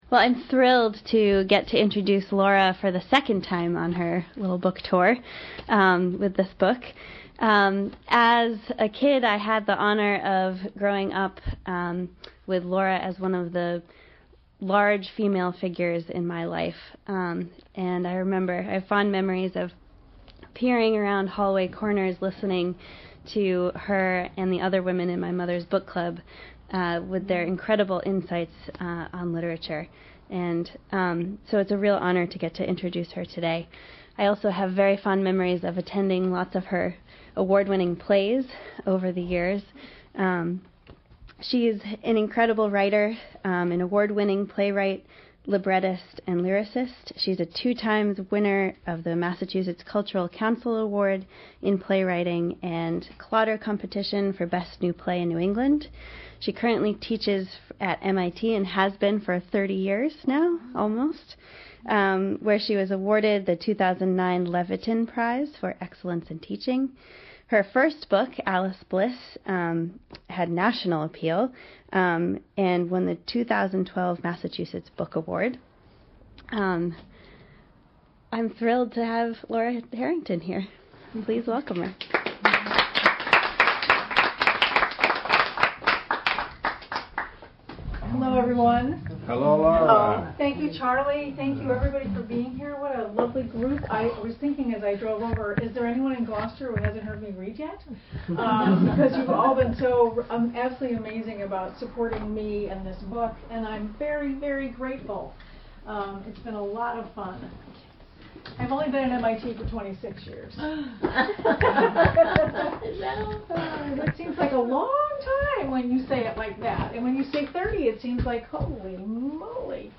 November reading.